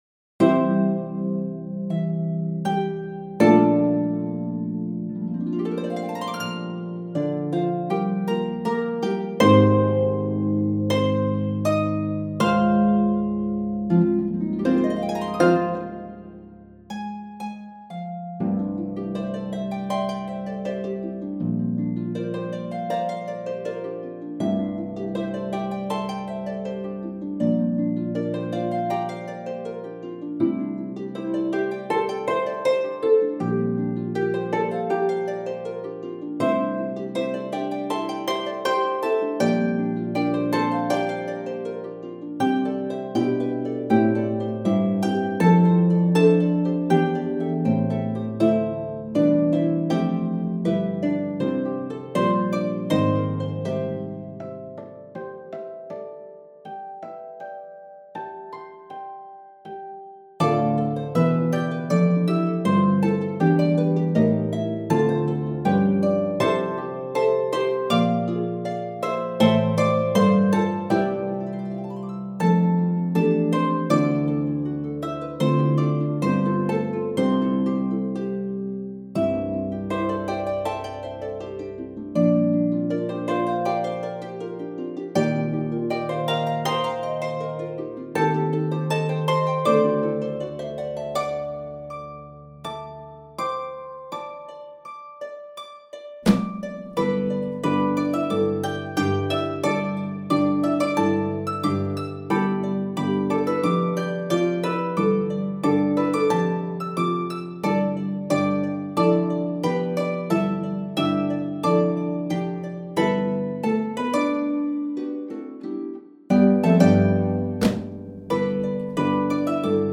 Chinese folk song
harp quartet
plus an optional line for bass or electric harp.